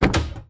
car-door-open-2.ogg